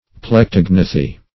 Plectognathi \Plec*to"gna*thi\, n. pl.